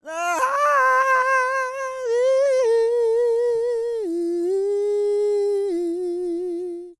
E-CROON 3033.wav